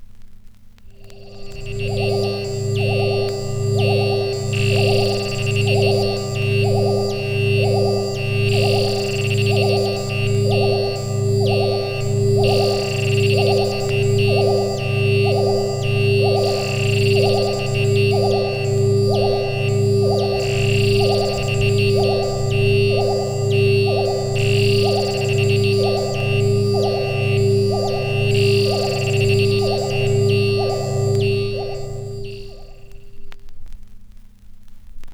• the electronic swamp - radio effect - horror vinyl.wav
Recorded from Sound Effects - Death and Horror rare BBC records and tapes vinyl, vol. 13, 1977.
the_electronic_swamp_-_radio_effect_-_horror_vinyl_f3A.wav